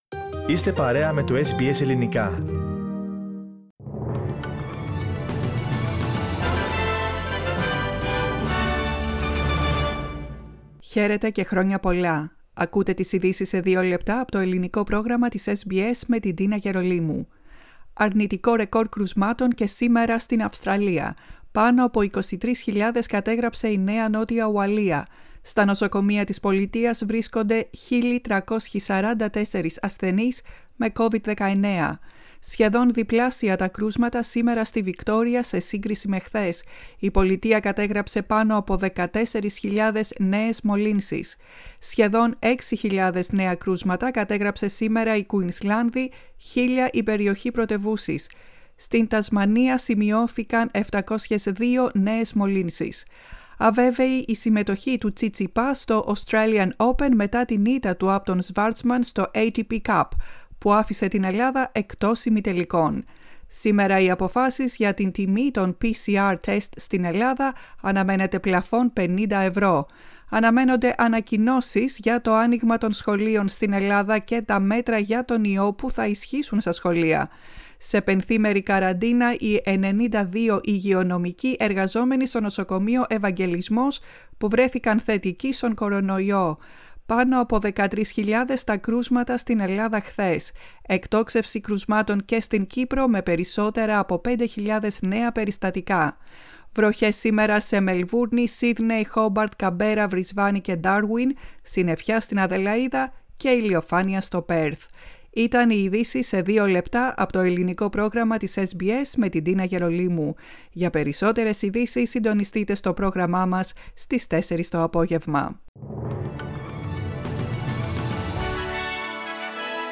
Listen to the news in brief in Greek for Tuesday 4th of January 2022.